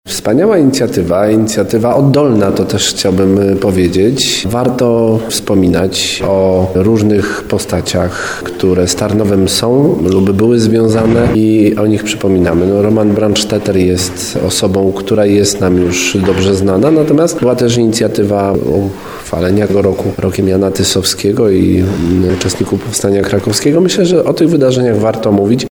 mówił prezydent Tarnowa Jakub Kwaśny